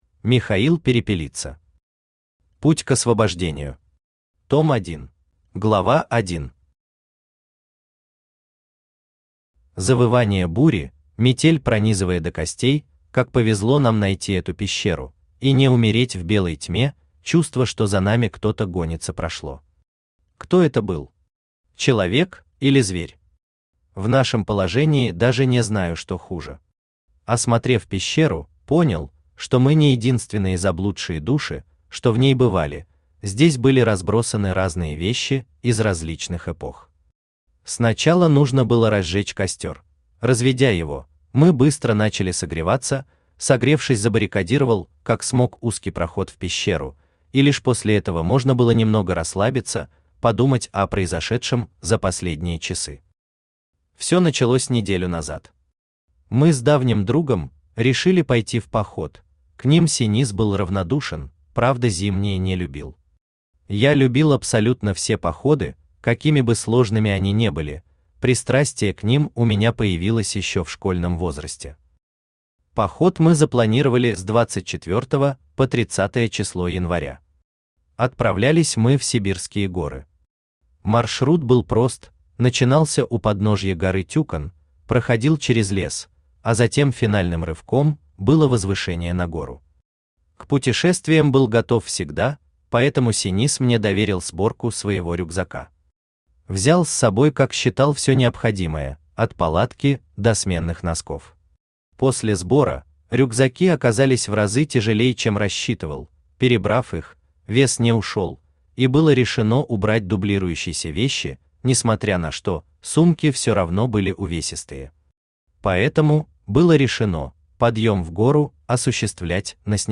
Аудиокнига Путь к освобождению. Том 1 | Библиотека аудиокниг
Том 1 Автор Михаил Алексеевич Перепелица Читает аудиокнигу Авточтец ЛитРес.